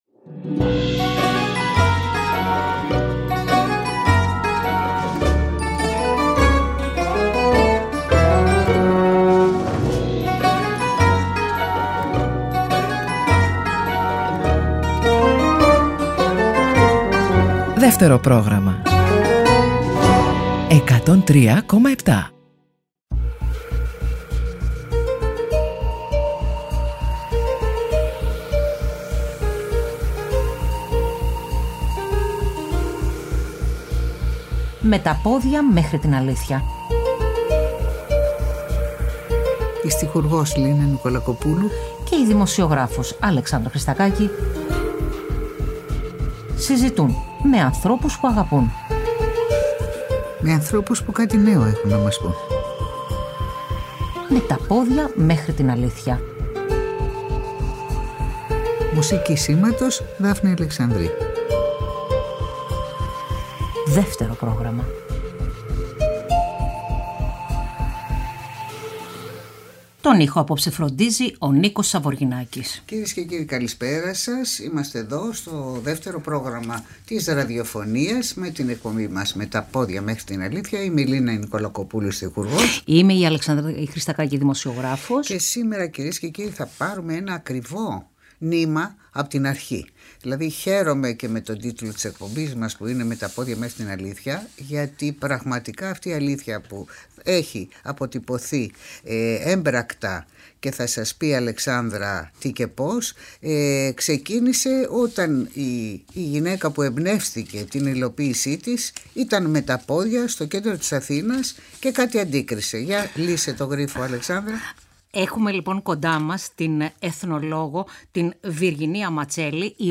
Συζήτησαν με την στιχουργό Λίνα Νικολακοπούλου